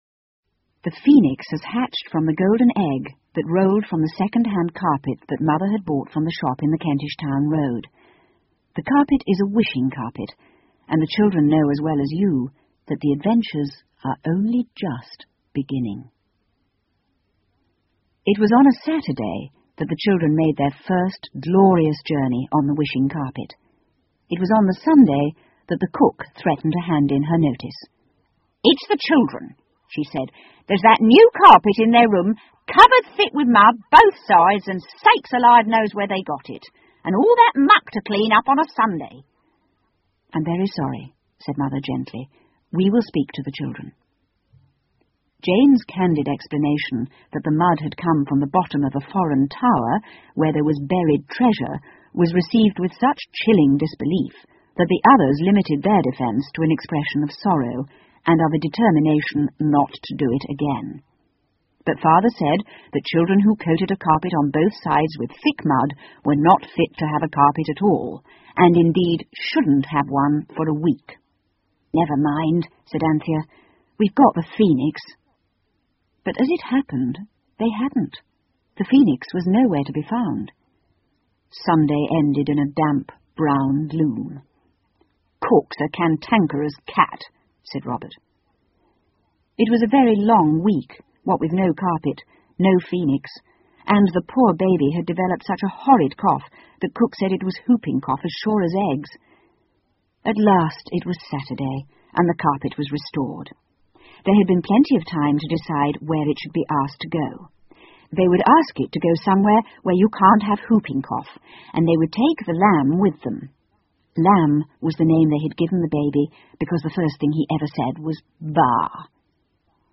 凤凰与魔毯 The Phoenix and the Carpet 儿童英语广播剧 4 听力文件下载—在线英语听力室